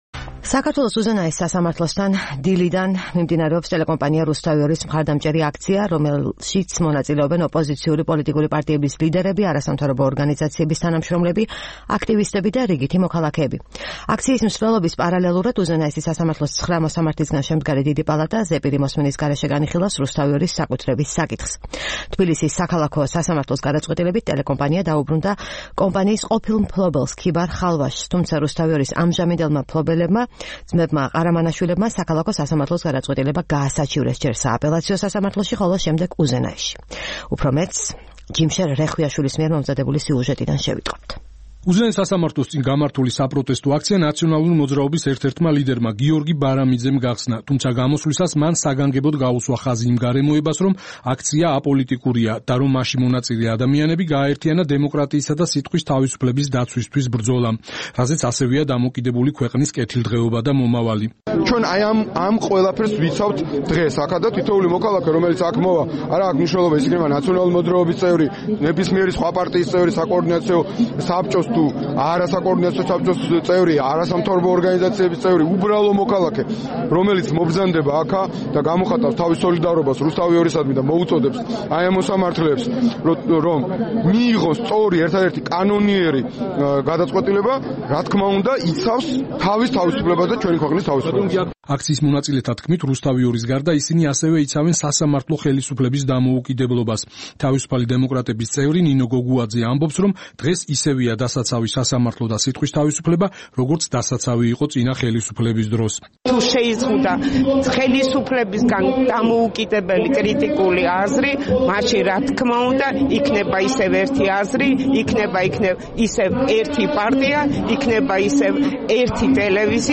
საპროტესტო აქცია უზენაესი სასამართლოს წინ